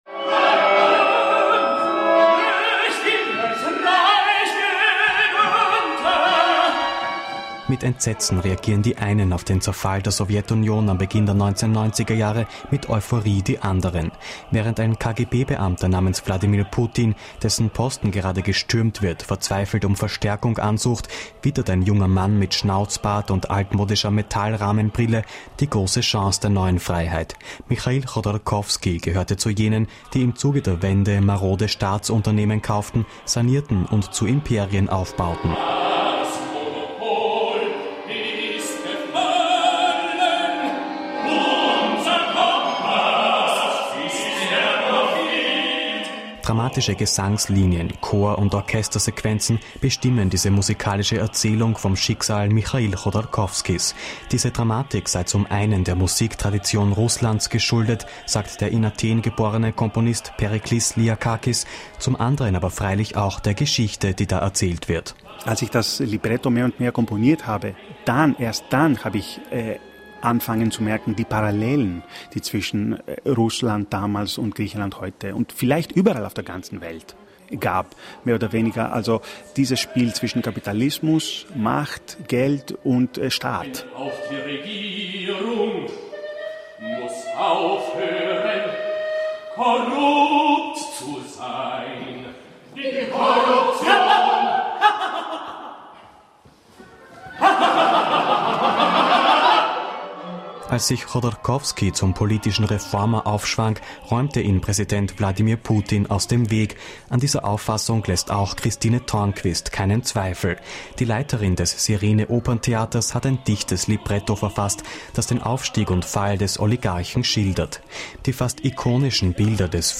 Dramatische Gesangslinien, Chor- und Orchestersequenzen bestimmen diese musikalische Erzählung vom Schicksal Michail Chodorkowskis.